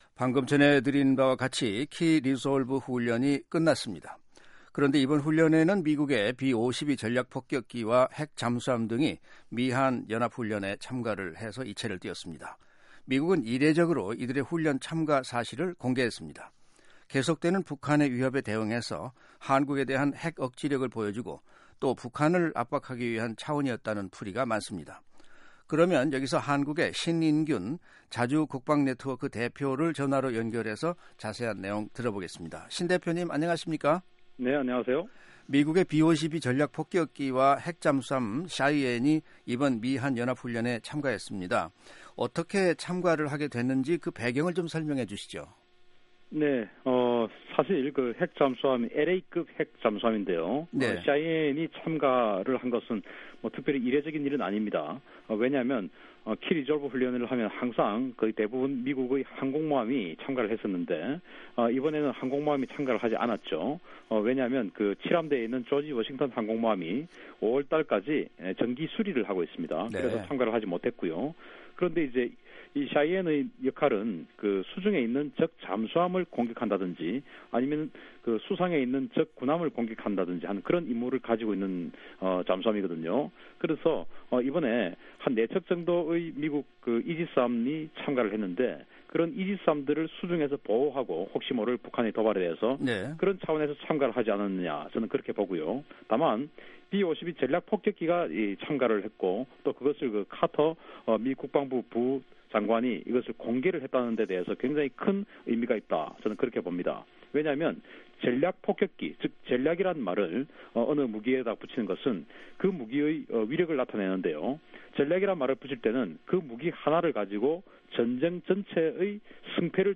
[인터뷰]
전화로 연결해 자세한 내용 들어보겠습니다.